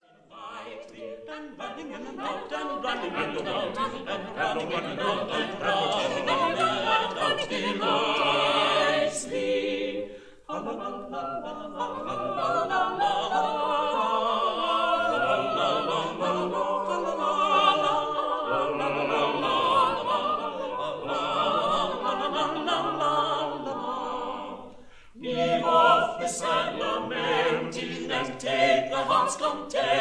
soprano
counter-tenor
tenor
bass